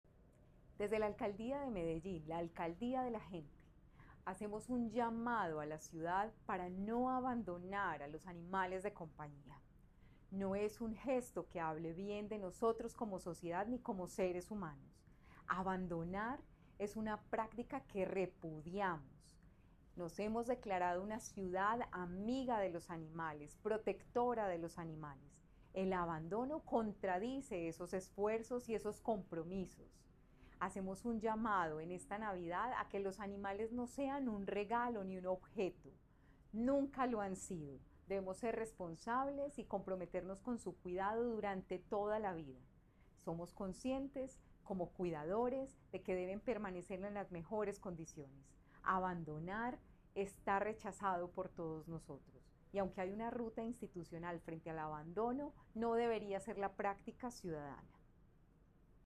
Declaraciones-de-la-secretaria-de-Medio-Ambiente-Marcela-Ruiz-2.mp3